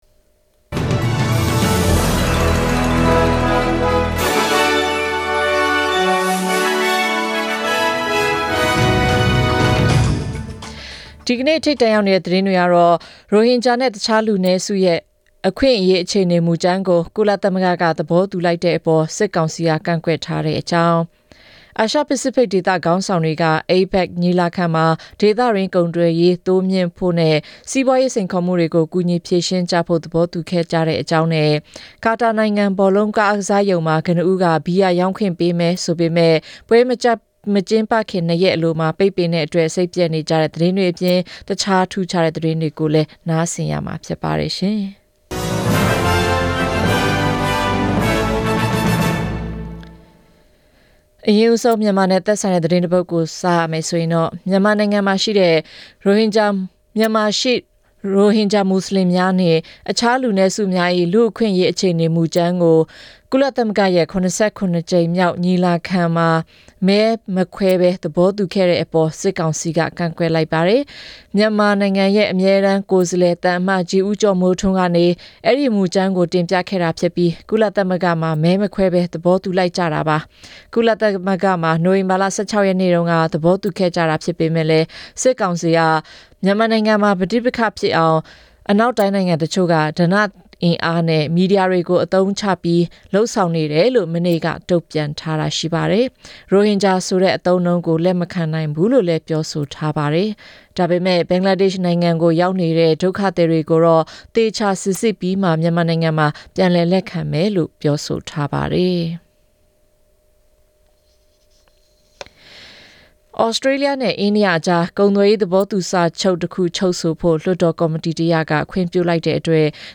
နိုဝင်ဘာလ ၁၉ ရက် သတင်းအတိုအထွာများ